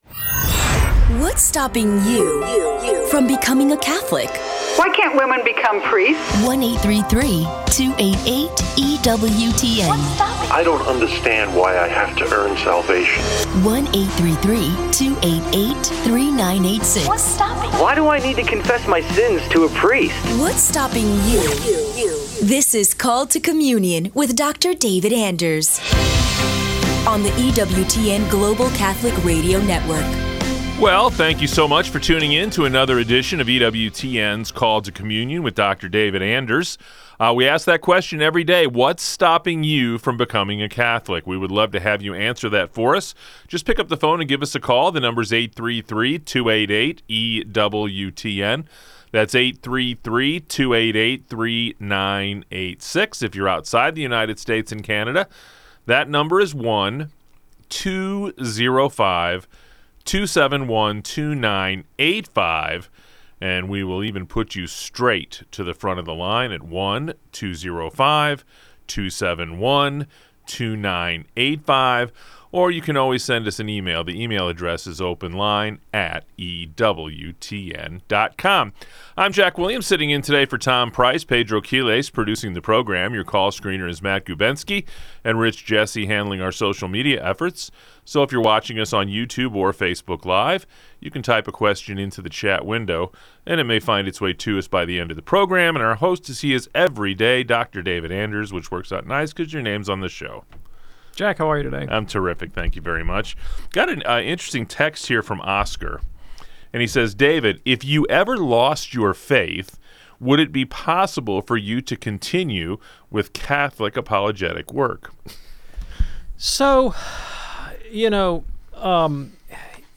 The tender topic of coping with loss is addressed as a grieving caller seeks comfort after losing a child.